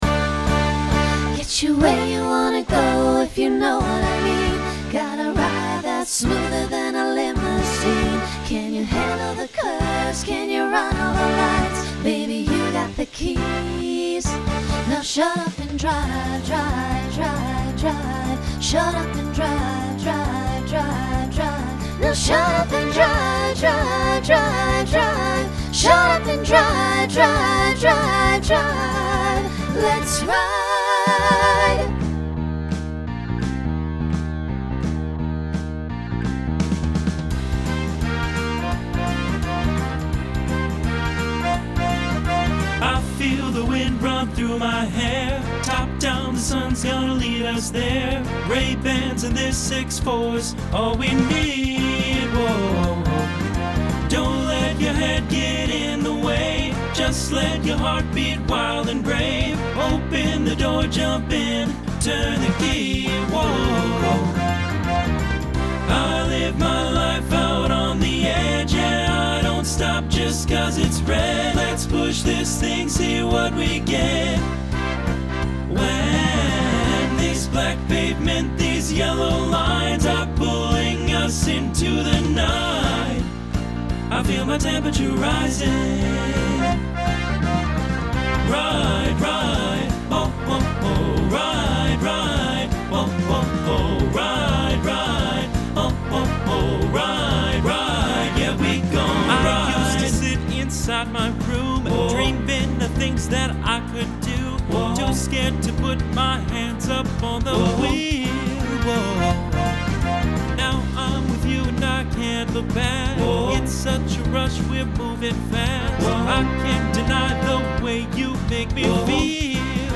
Genre Pop/Dance , Rock
Transition Voicing Mixed